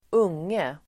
Uttal: [²'ung:e]